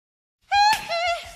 Reactions
Perfect For Unblocked Sound Buttons, Sound Effects, And Creating Viral Content.